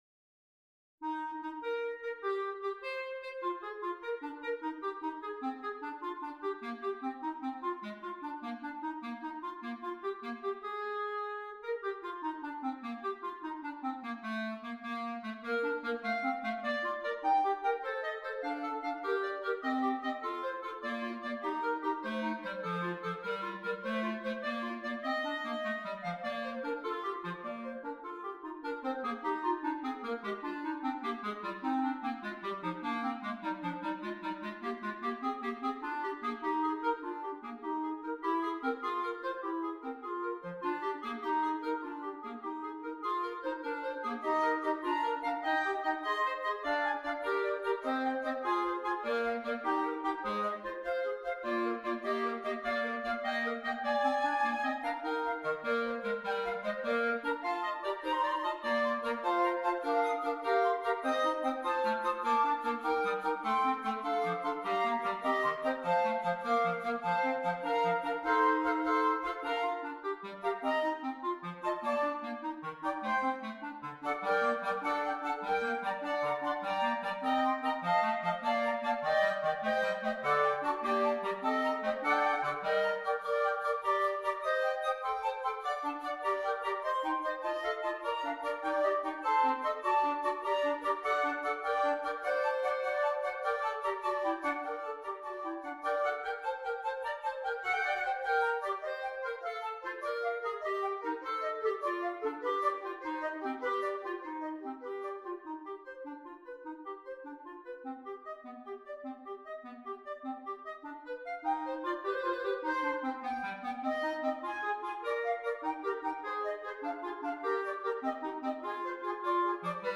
2 Flutes, 2 Clarinets